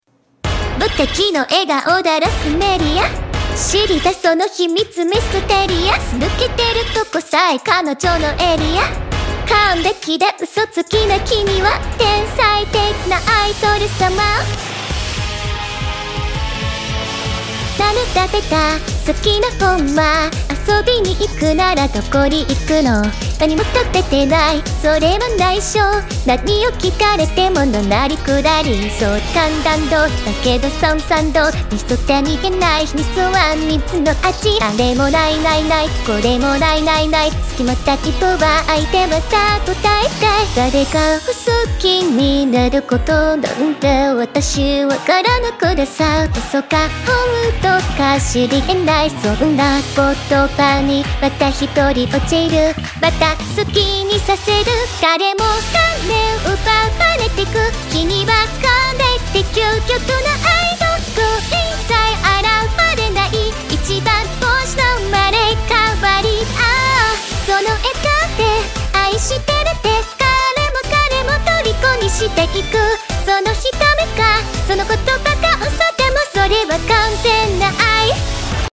唱歌表现
唱歌：支持